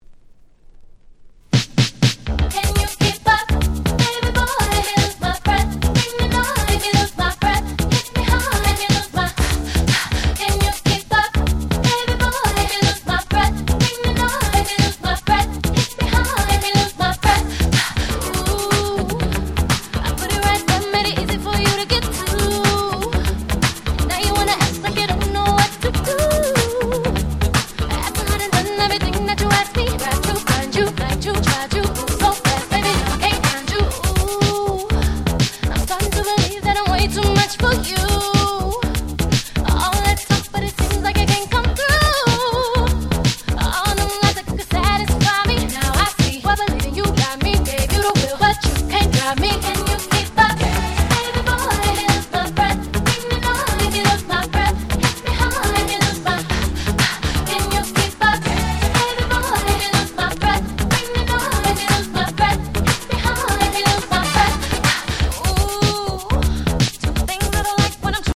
04' Very Nice Party Tracks !!